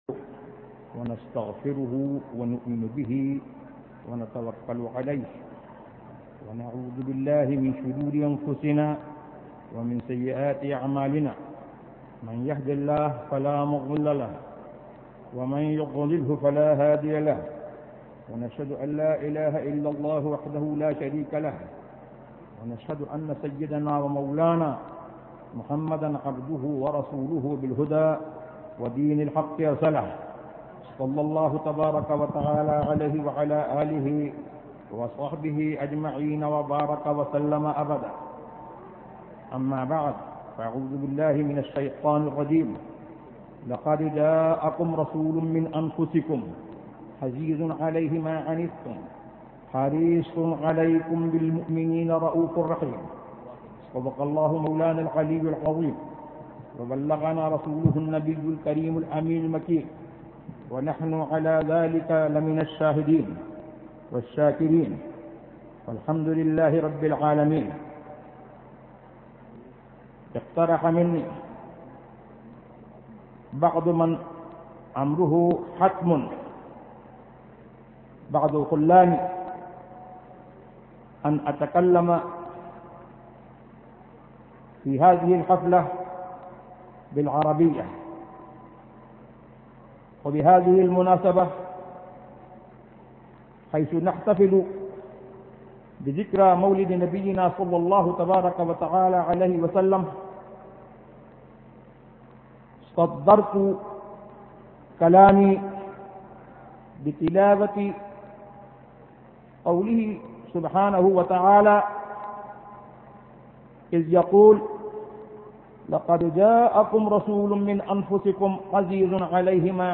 Category : Speeches | Language : Arabic